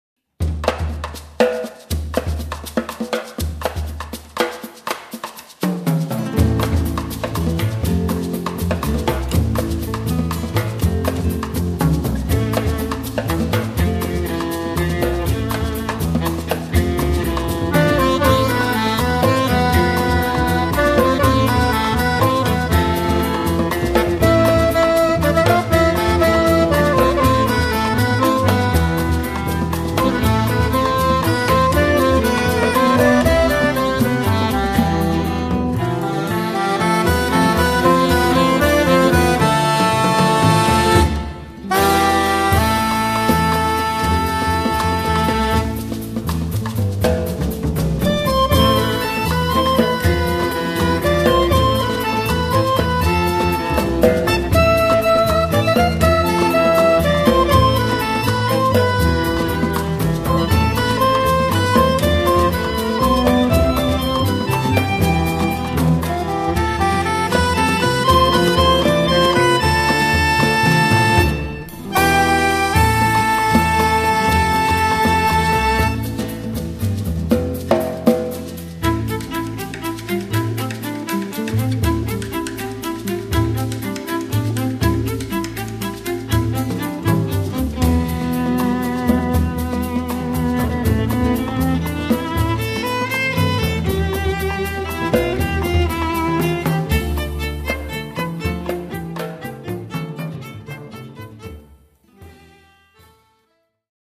fisarmonica
sax soprano e clarinetto
viola
chitarra
contrabbasso
batteria
percussioni
jazz, musica mediterranea, popolare e tango